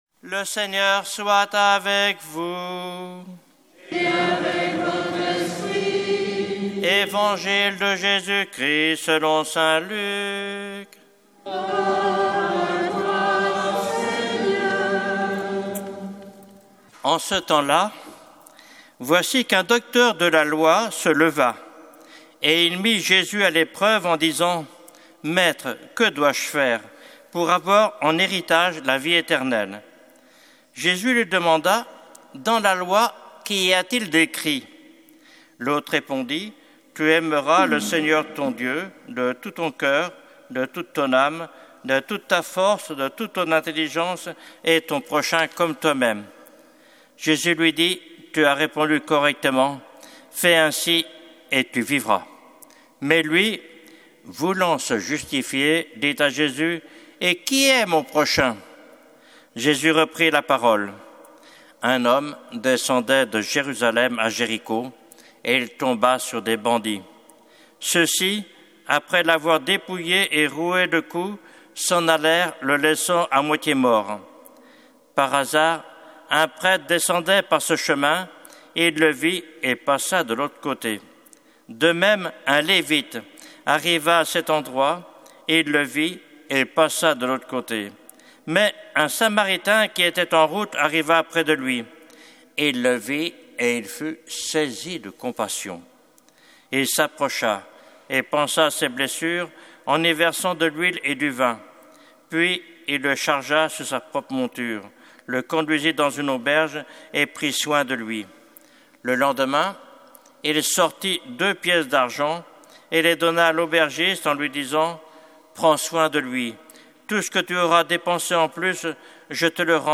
Évangile de Jésus Christ selon saint Luc avec l'homélie